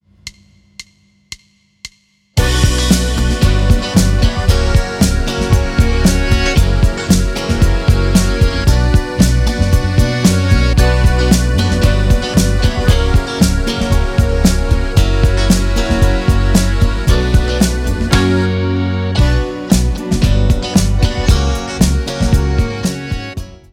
Staram się używać w projektach naturalne instrumenty akustyczne.
Podkład w wysokiej jakości w wersjach mp3 oraz wav
Podkład muzyczny „Ojczyzno ma” do pobrania za darmo tutaj.